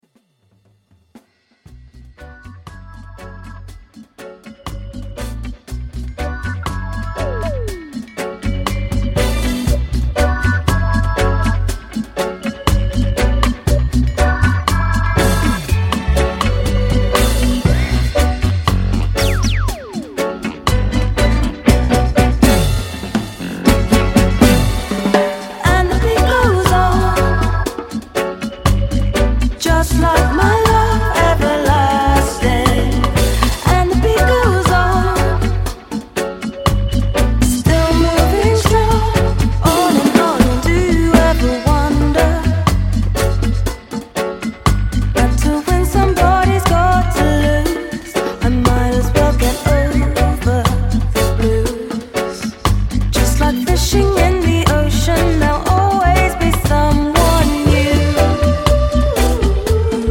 女性ヴォーカルをフィーチャーしておりB面にはダブも収録！